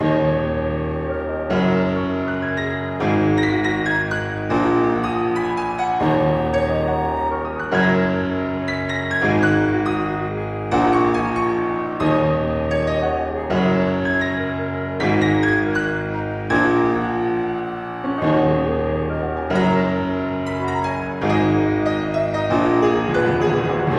掉落反弹卡通音
描述：先是一个哨声表示掉落，然后用一个定音鼓表示反弹。
标签： 卡通片 滑动口哨 定音鼓反弹
声道立体声